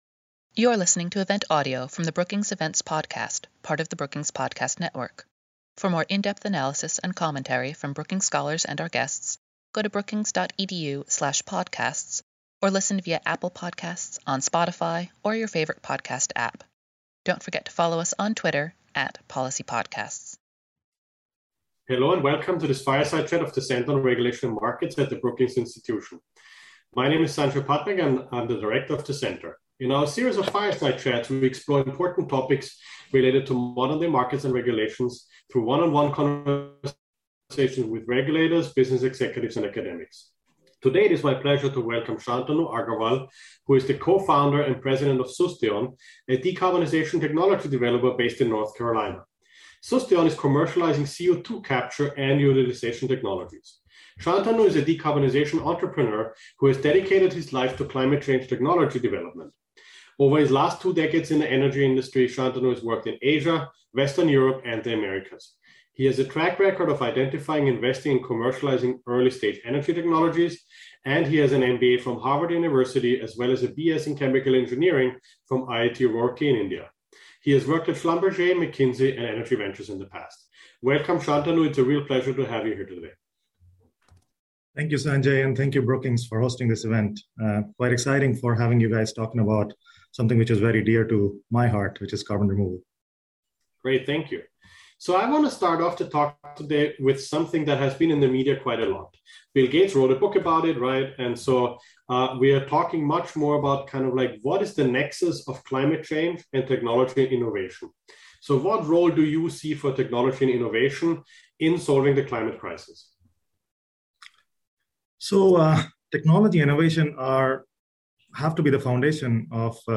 Moderated conversation